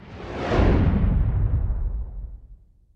Whoosh